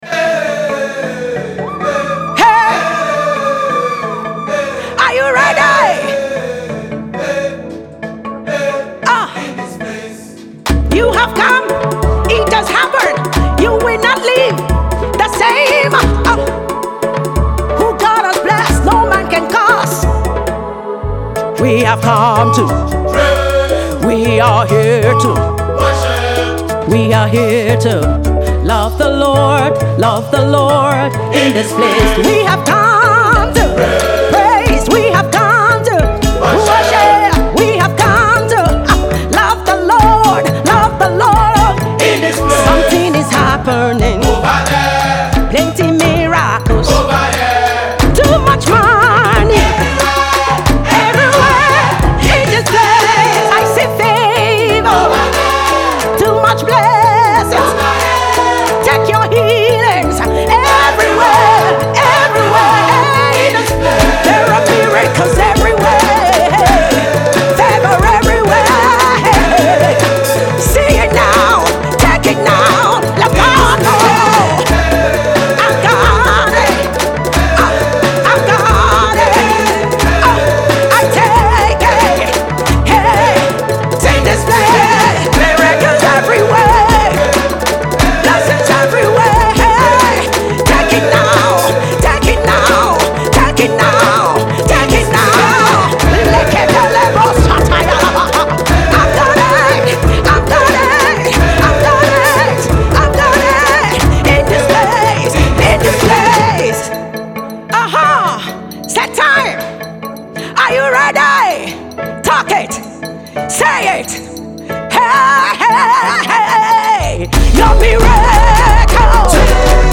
as she seeks to turn the tides with this declarative anthem.